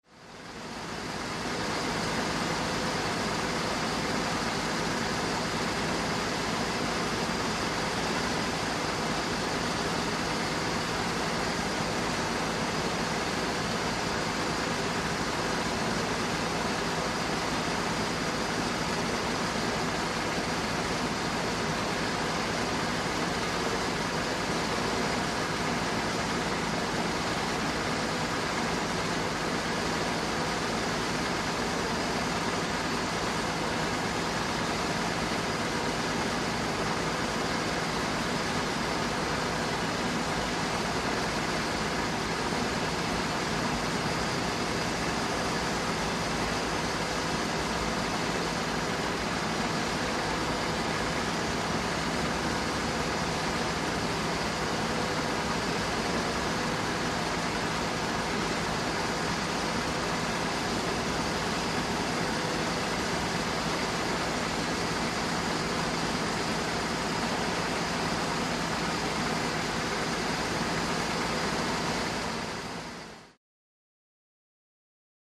Chevrolet Impala, Idle, Medium Cu.